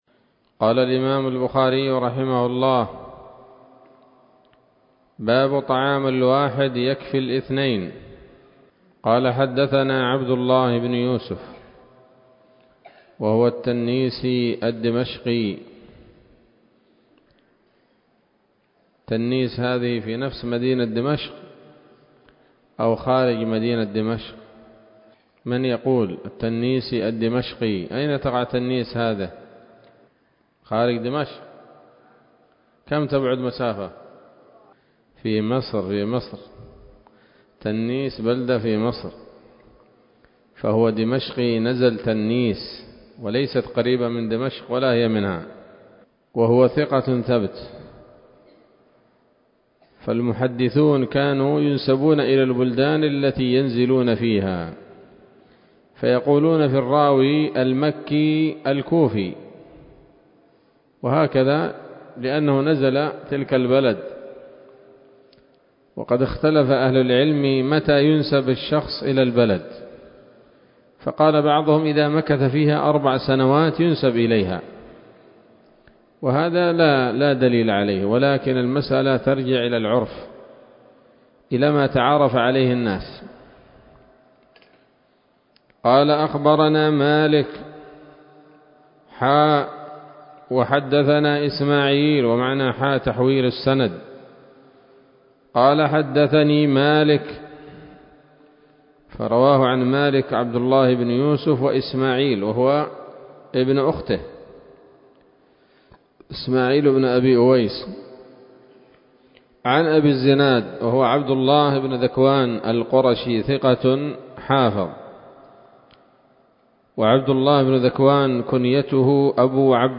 الدرس الثامن من كتاب الأطعمة من صحيح الإمام البخاري